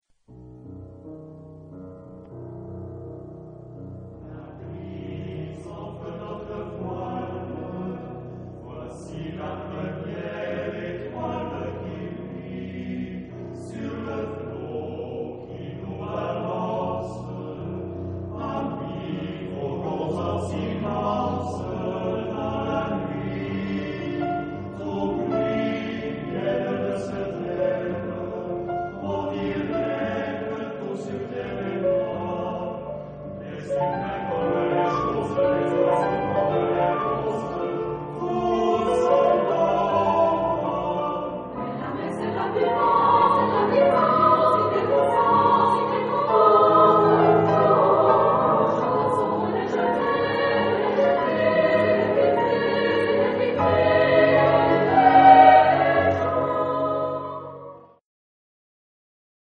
Genre-Style-Form: Secular ; Contemporary ; Lied
Type of Choir: SSAATTBB  (8 mixed voices )
Instrumentation: Piano  (1 instrumental part(s))